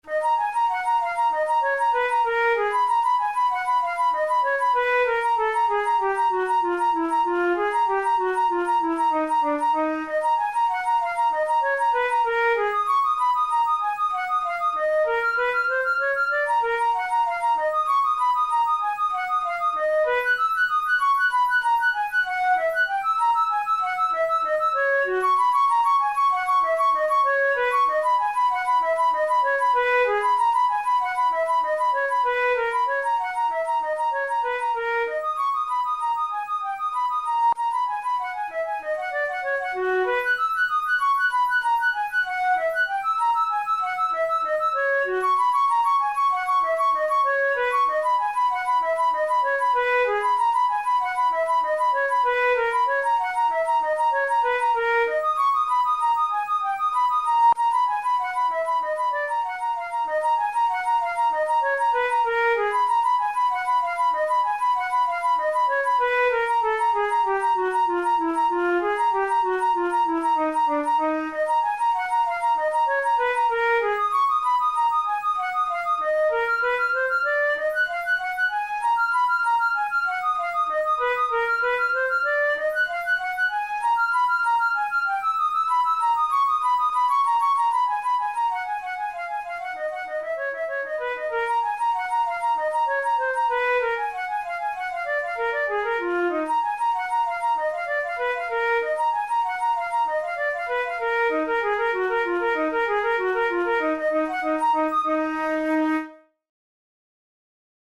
The animated cut-time Moderato in D-sharp minor we present today is the fourteenth piece from Danish flutist and composer Joachim Andersen's Twenty-Four Etudes for Flute, Op. 30.
Categories: Etudes Romantic Written for Flute Difficulty: advanced